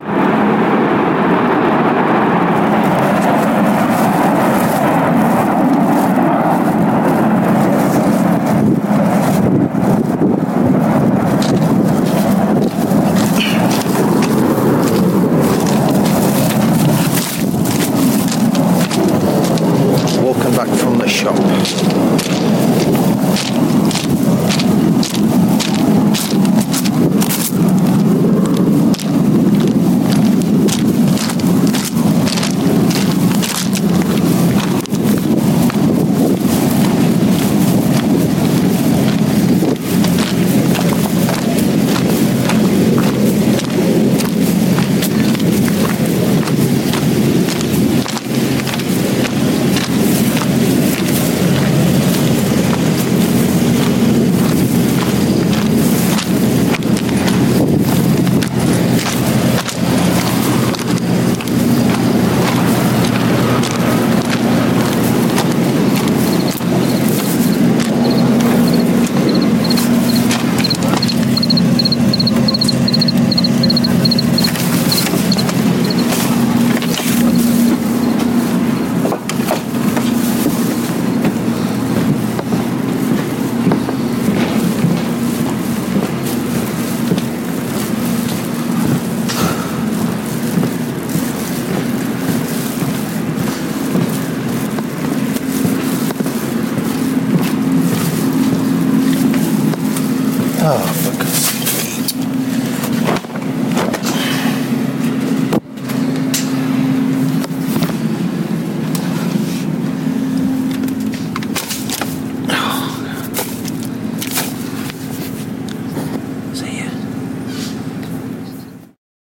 walking back from the shop [soundscape]